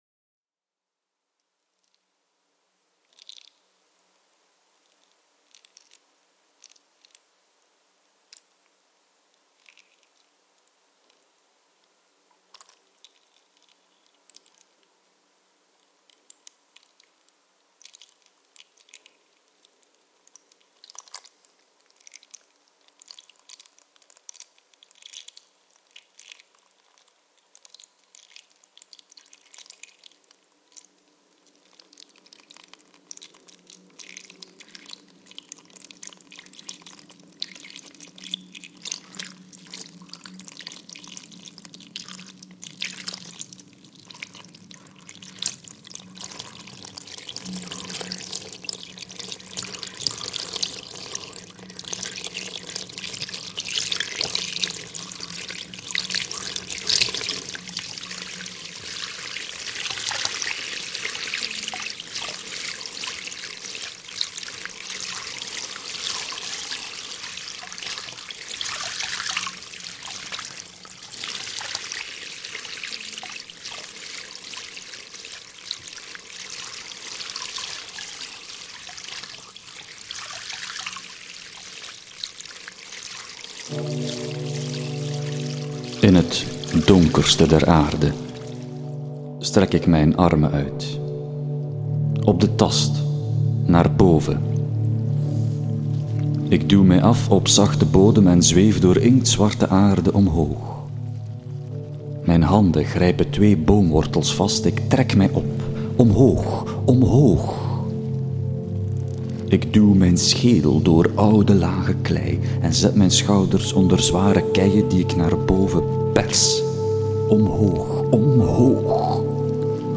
Dans le passé, quelles fontaines et quelles autres présences de l’eau trouvait-on dans ce qui n’était jadis qu’un village dans un marais ? Un récit audio
Een audioverhaal